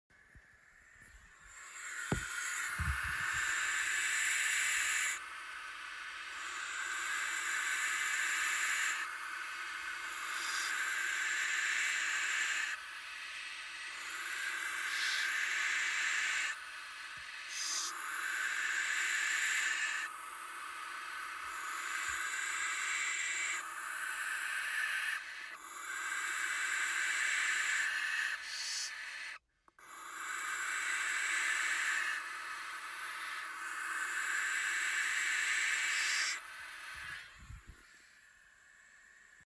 Звук шипения сипухи обыкновенной отпугивает врагов от гнезда